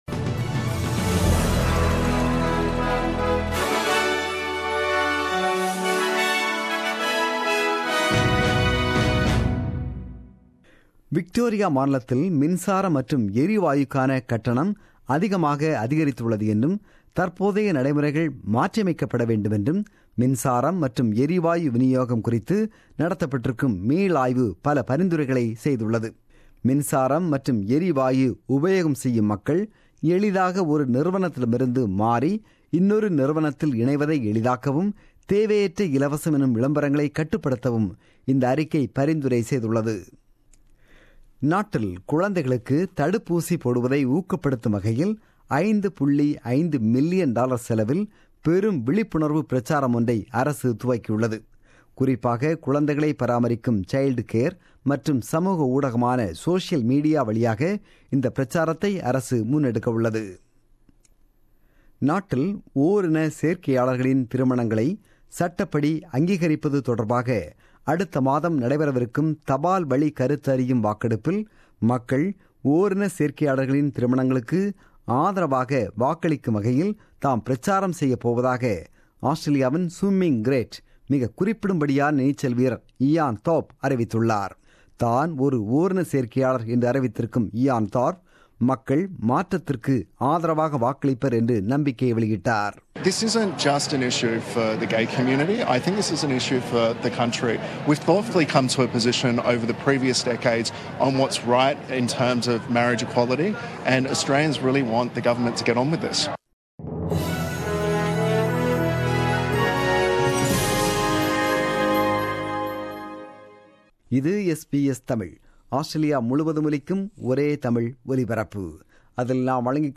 The news bulletin broadcasted on 13 August 2017 at 8pm.